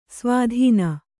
♪ svādhīna